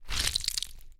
黏液 " 黏液13
描述：橡胶抗压球被压扁 使用Rode NT1a和Sound Devices MixPre6录制
Tag: 戈尔 静噪 血液 恐怖效果 僵尸 粘液 恐怖 恐怖-FX 挤气 糊状